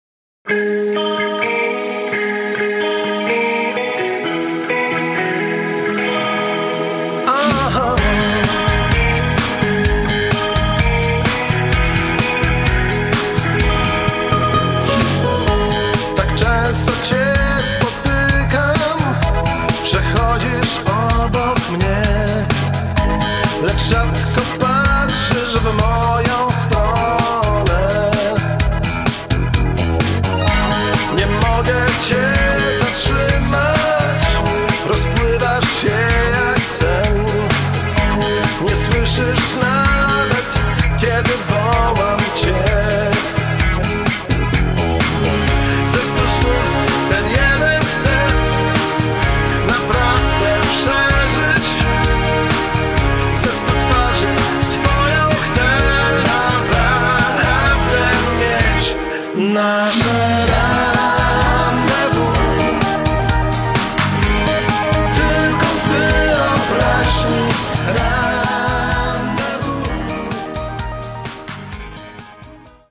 BAND DUET
VOC GUITAR KEYB BASS DRUMS TEKST